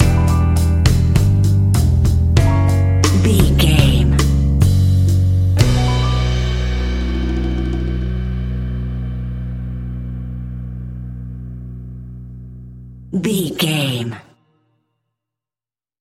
An exotic and colorful piece of Espanic and Latin music.
Aeolian/Minor
Slow
romantic
maracas
percussion spanish guitar